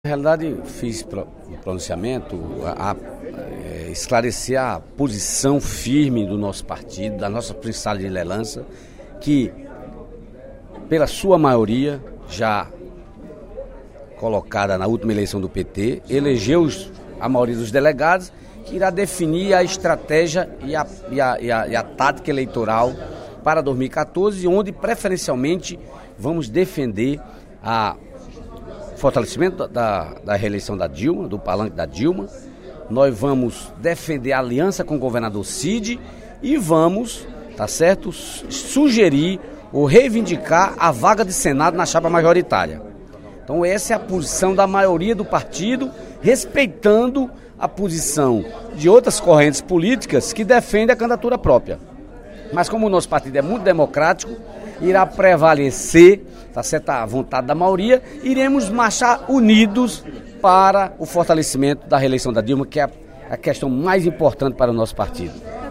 No primeiro expediente da sessão plenária desta terça-feira (18/02), Dedé considerou a importância de que a prerrogativa de criação de novos municípios retorne às casas legislativas estaduais.